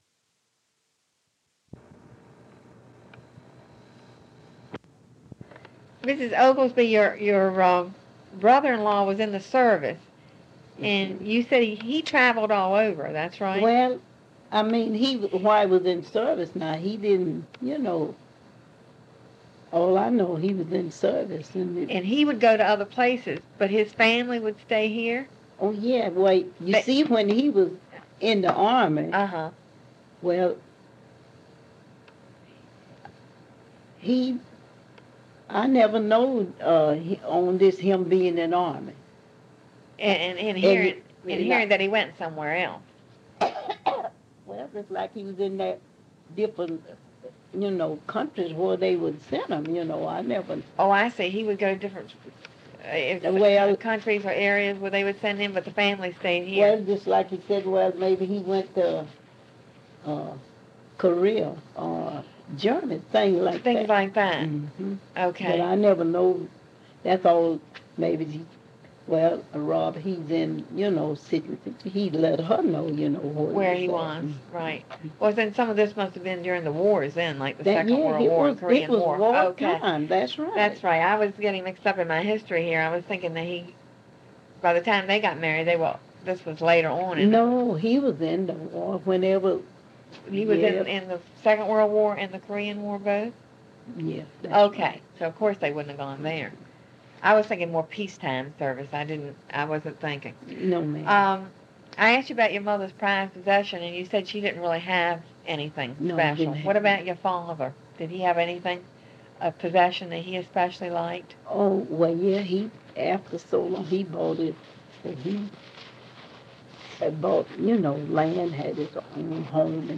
25:40--Interview ends after which the last five minutes of the file sounds like two people speaking in reverse.